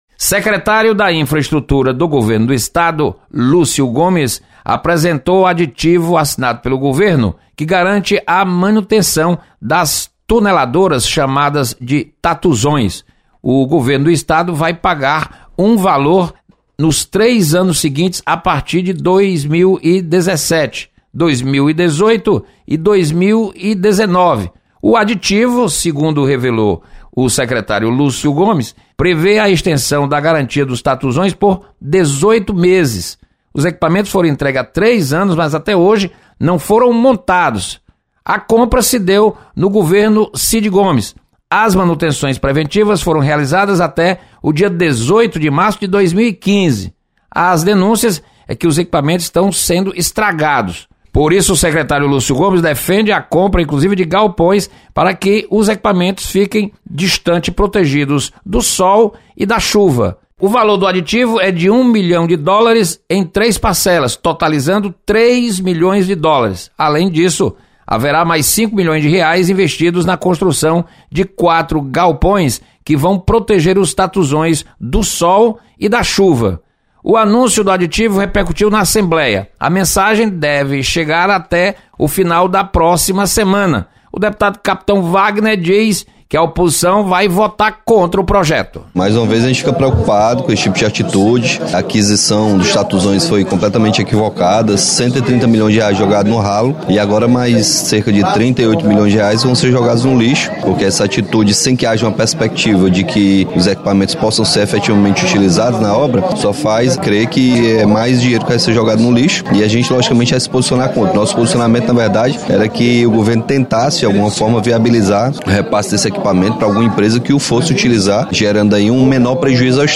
Deputados comentam sobre aditivo aos contratos de manutenção das tuneladoras.